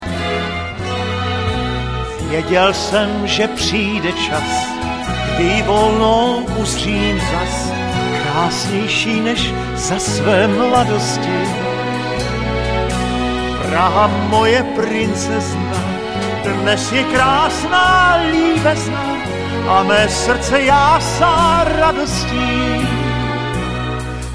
Studio A České televize (smyčce)